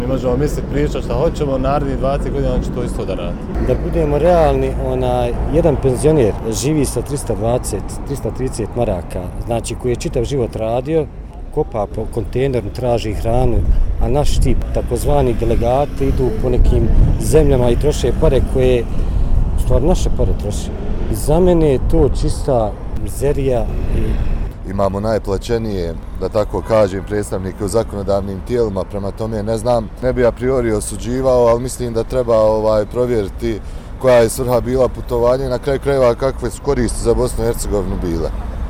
Sarajlije o putovanjima